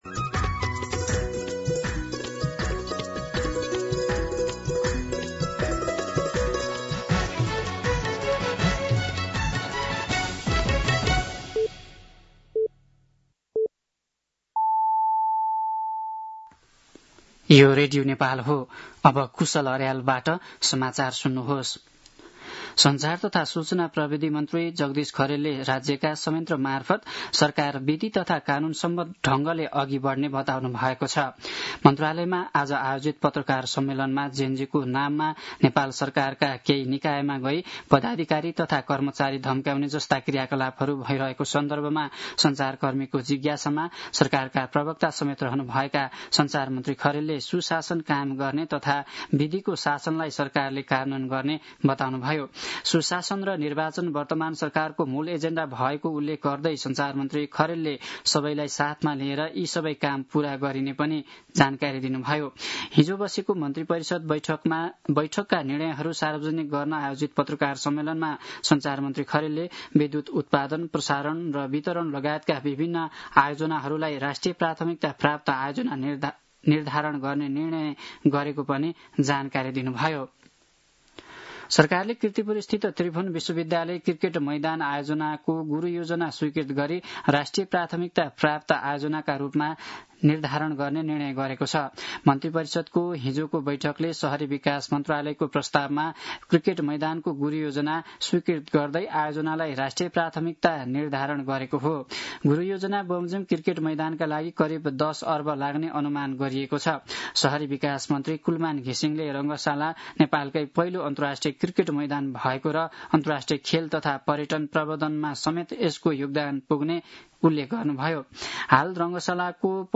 दिउँसो ४ बजेको नेपाली समाचार : ३१ असोज , २०८२
4-pm-Nepali-News-7.mp3